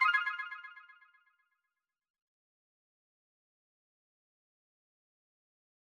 confirm_style_4_echo_003.wav